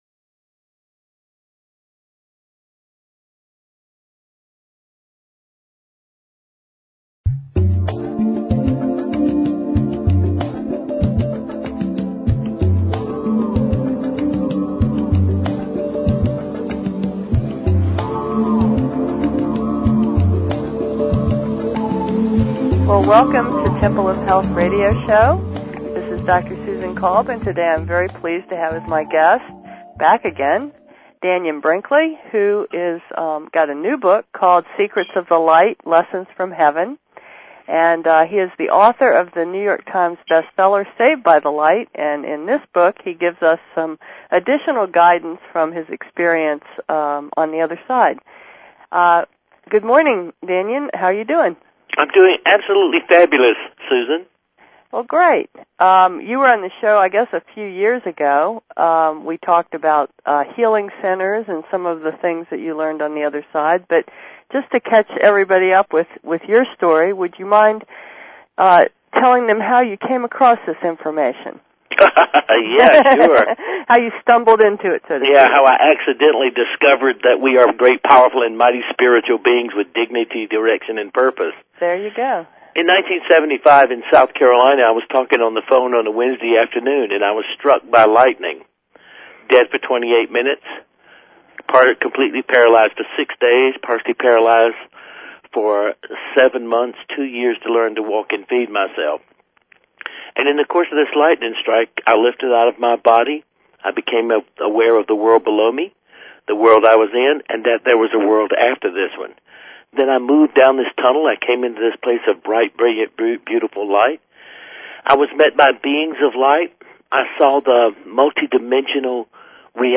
Talk Show Episode, Audio Podcast, Temple_of_Health_Radio_Show and Courtesy of BBS Radio on , show guests , about , categorized as
Dannion Brinkley Title: Secrets of the Light: Lessons From Heaven Dannion Brinkley is an international best selling author.